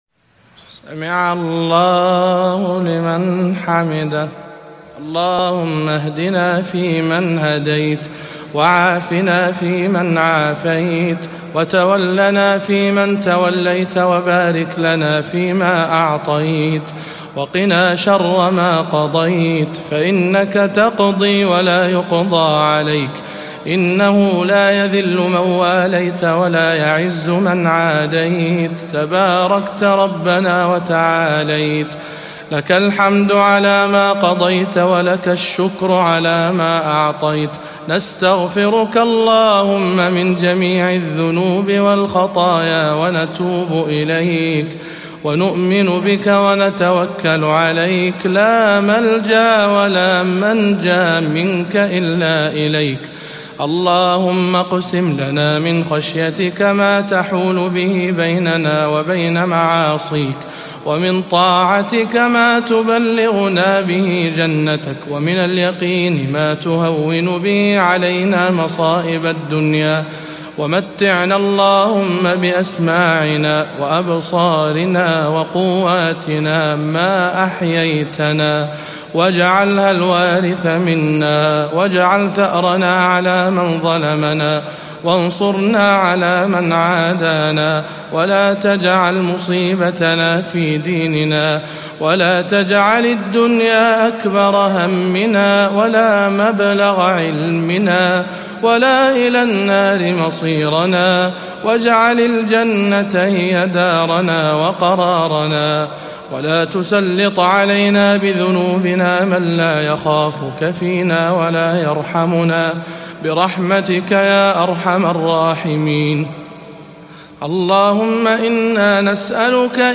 أدعية القنوت
رمضان 1438 هـ